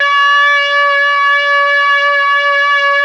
RED.BRASS 31.wav